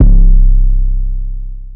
Cold Heart 808
Cold-Heart-808.wav